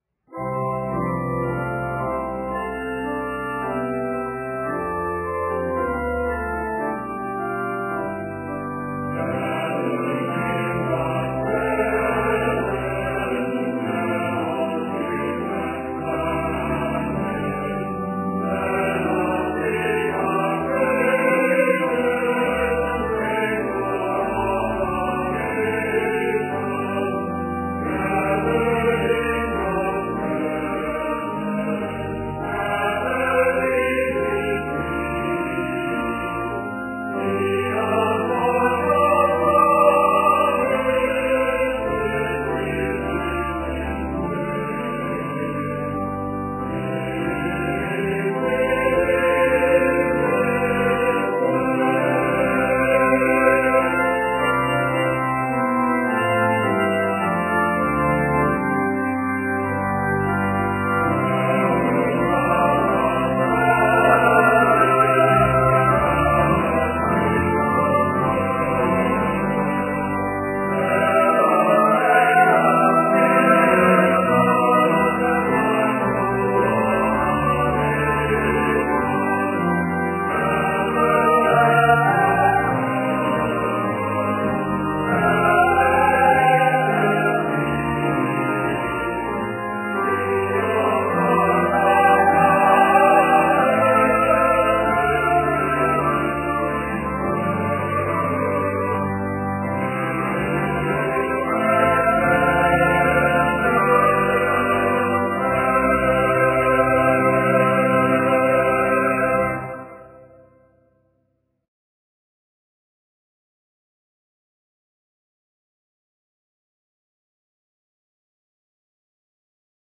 Voicing: Two-part